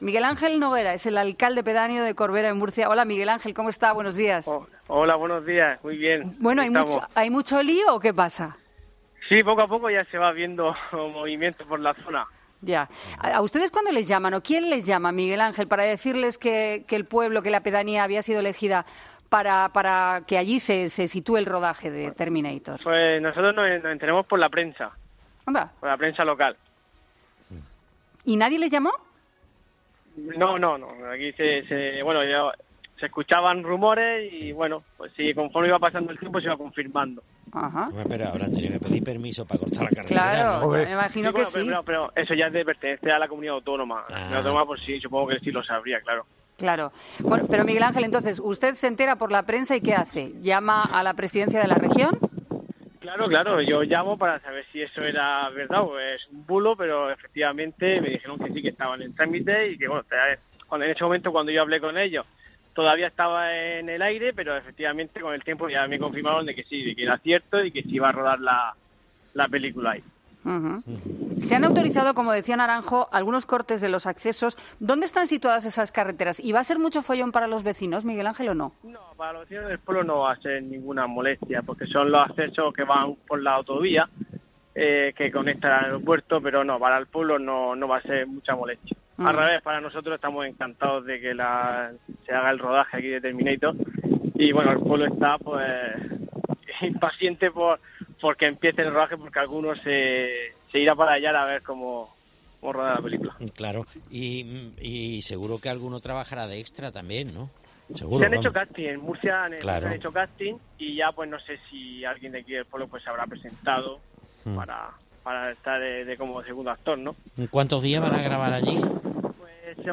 Escucha la entrevista a Miguel Ángel Noguera, Alcalde de la pedanía de Corvera (Murcia)